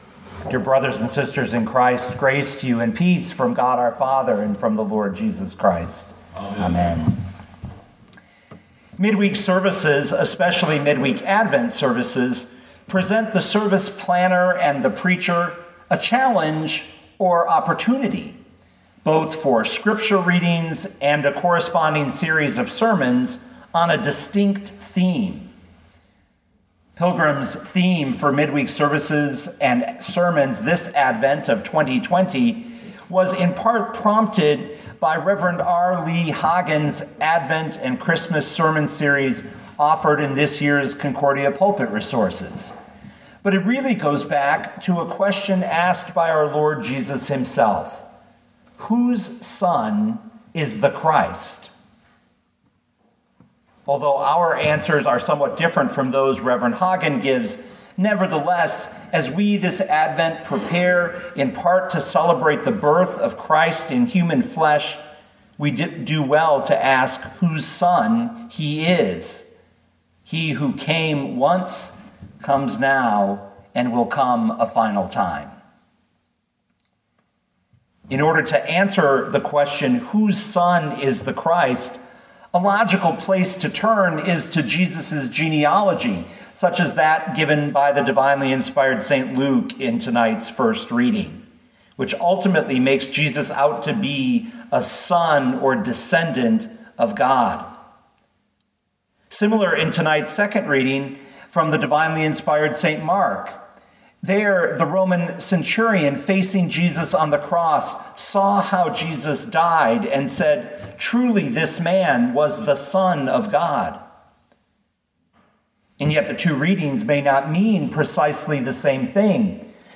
Mark 15:33-39 Listen to the sermon with the player below, or, download the audio.